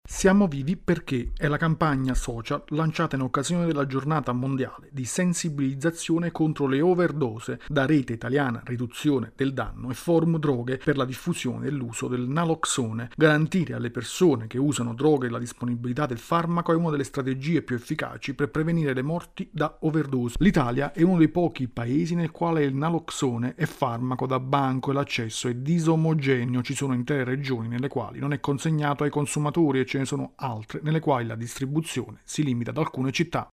Al via la campagna social di Rete italiana riduzione del danno e Forum droghe per l’accesso al naloxone. Il servizio